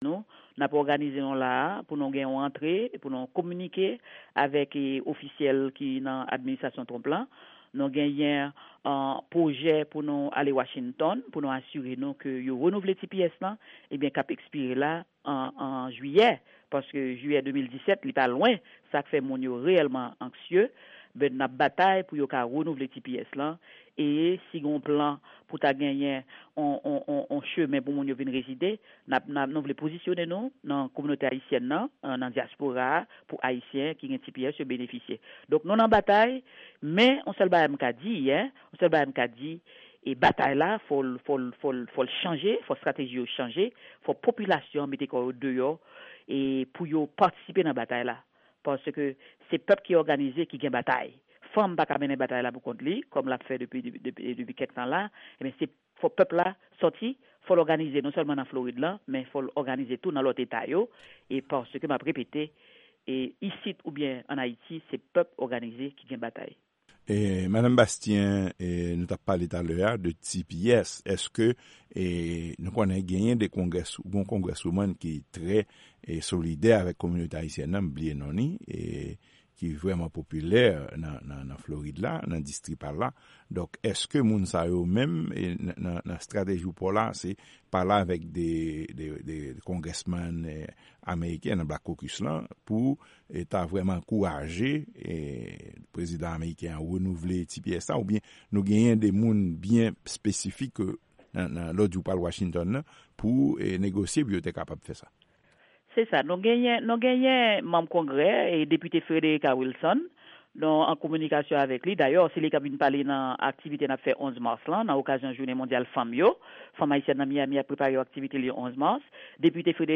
Ekstrè entèvyou